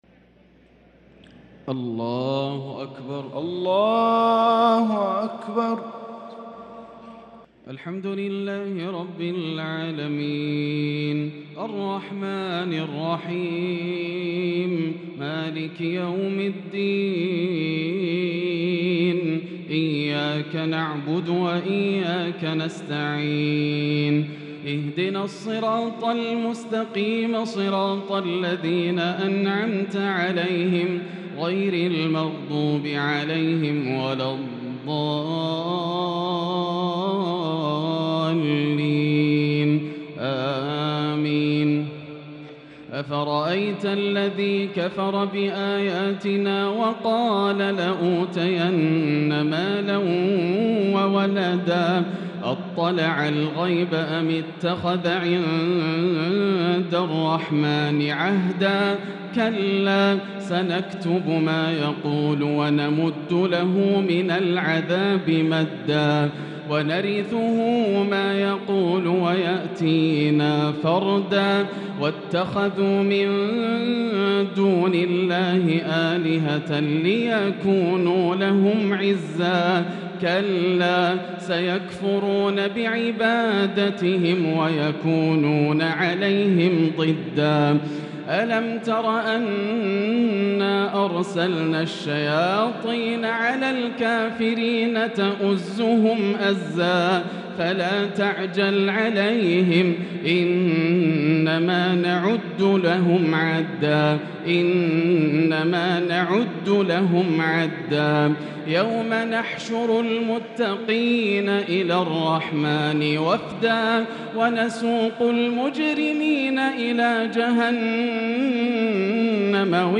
تراويح ليلة 21 رمضان 1443هـ من سورتي مريم (77-98) و طه (1-82) Taraweeh 21 th night Ramadan 1443H Surah Maryam and Taa-Haa > تراويح الحرم المكي عام 1443 🕋 > التراويح - تلاوات الحرمين